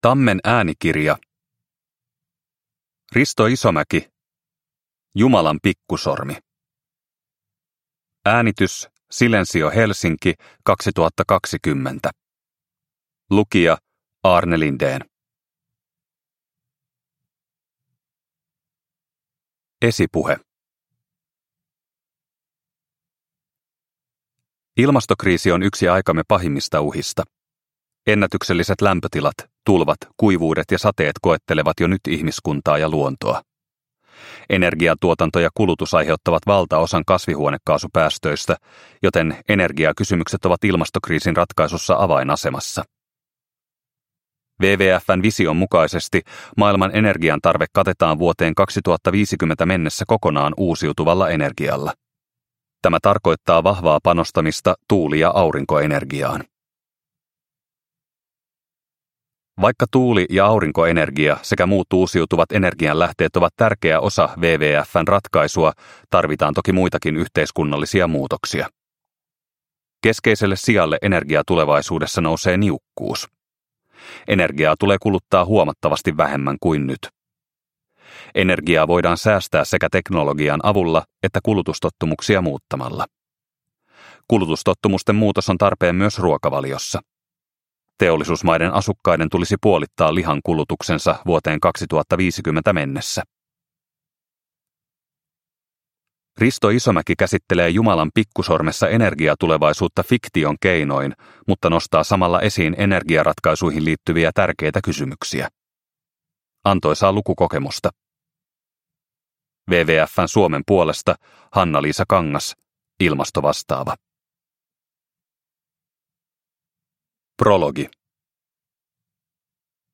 Jumalan pikkusormi – Ljudbok – Laddas ner